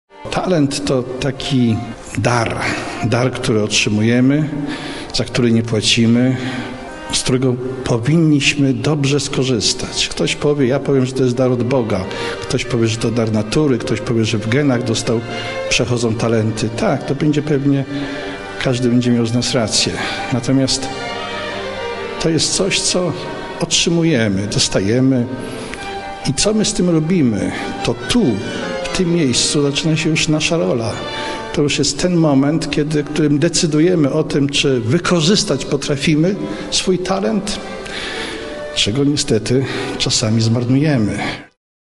W piątek (2 lipca) podczas uroczystej gali w Filharmonii Łódzkiej marszałek województwa łódzkiego Grzegorz Schreiber wręczył stypendia naukowe i artystyczne najzdolniejszym twórcom, uczniom i studentom.